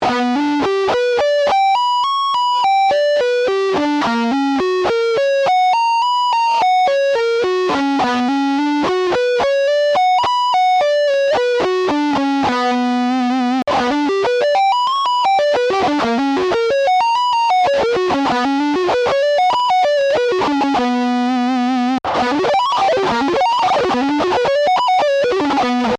Example 3: This example only uses one guitar technique (sweep picking), however, the challenge here is in transitioning from playing 6 notes per beat (sixteenth note triplets) to 4 notes per beat (regular sixteenth notes) as well as incorporating some 2 hand tapping.
Sweep Picking Arpeggio Tab